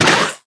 击中zth070518.wav
通用动作/01人物/02普通动作类/击中zth070518.wav
• 声道 單聲道 (1ch)